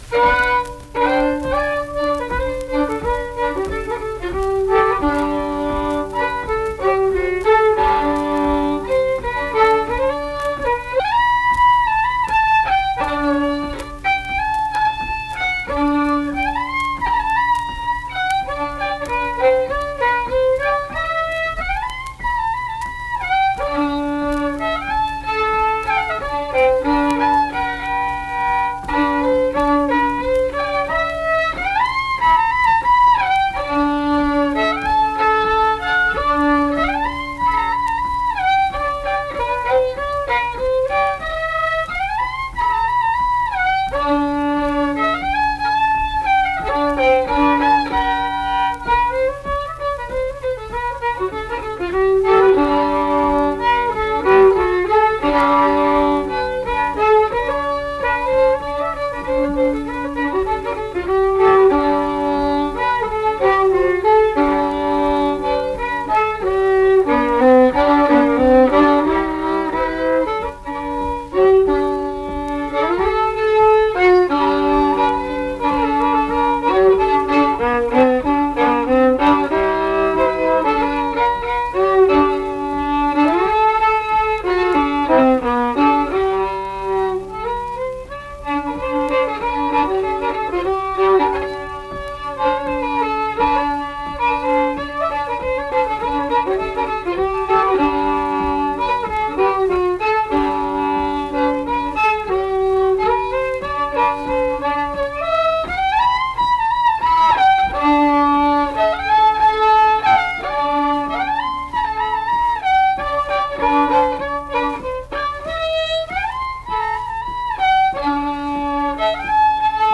Accompanied guitar and unaccompanied fiddle music performance
Instrumental Music
Fiddle